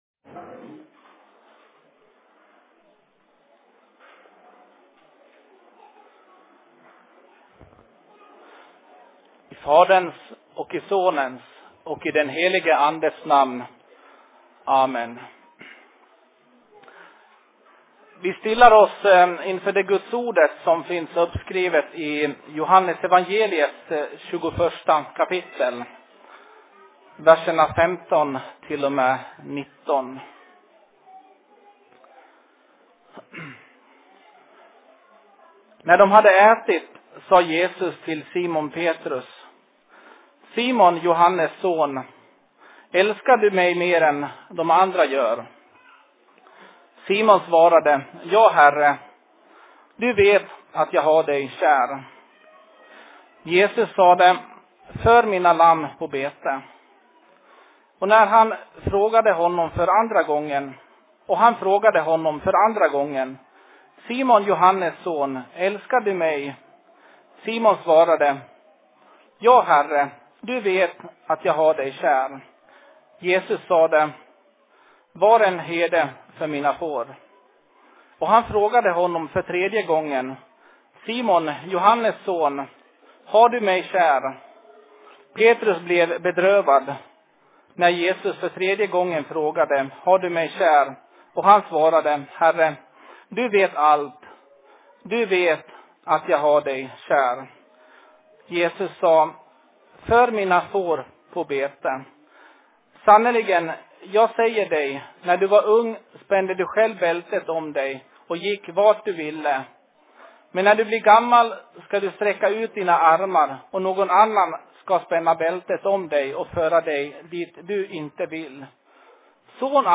Se Predikan I Dalarnas Fridsförening 18.09.2016